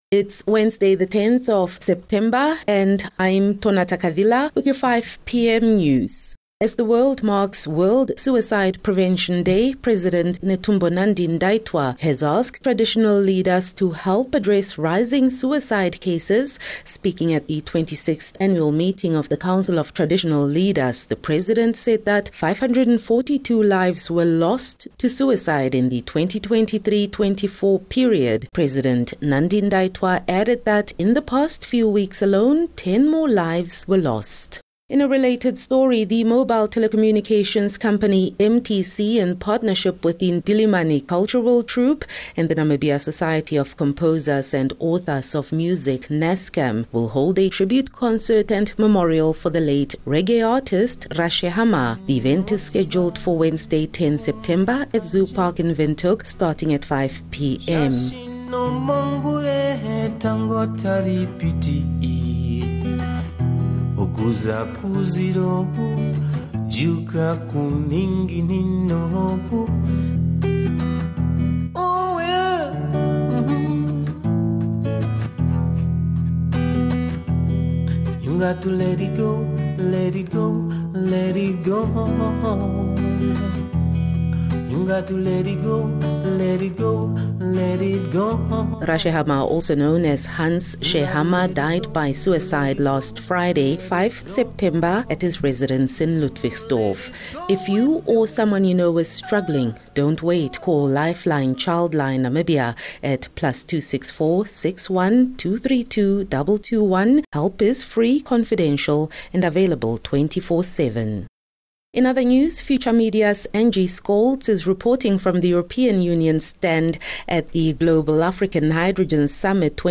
10 Sep 10 September - 5 pm news